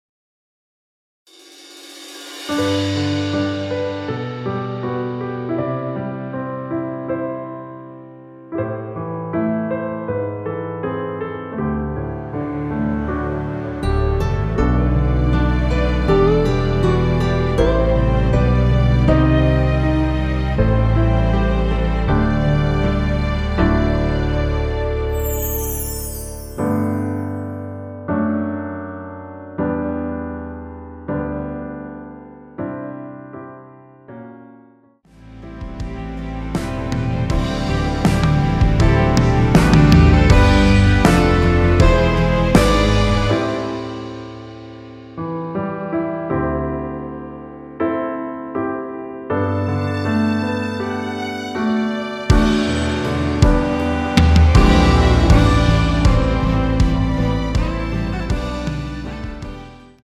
원키에서(-6)내린 (1절+후렴) MR입니다.
앞부분30초, 뒷부분30초씩 편집해서 올려 드리고 있습니다.